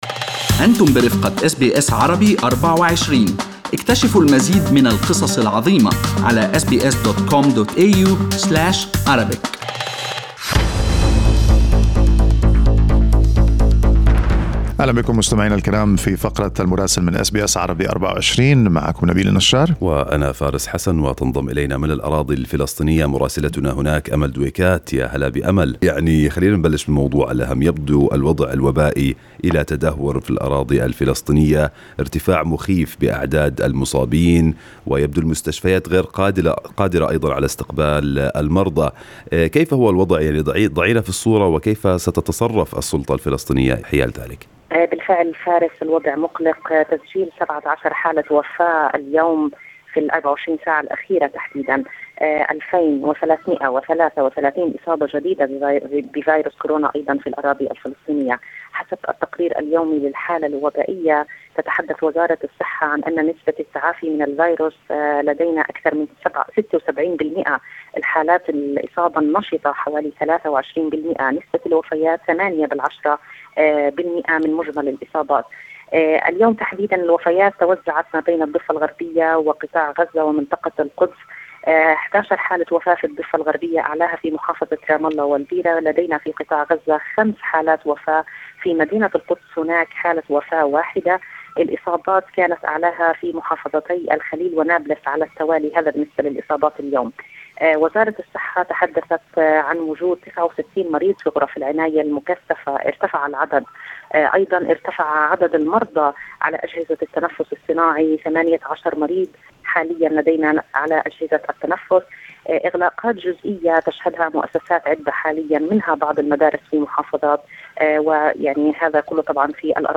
يمكنكم الاستماع إلى تقرير مراسلتنا في رام الله بالضغط على التسجيل الصوتي أعلاه.